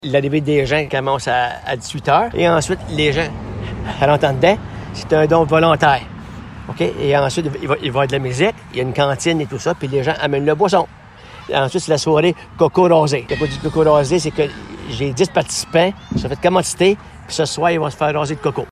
L’ancien conseiller municipal nous explique le déroulement de la levée de fonds :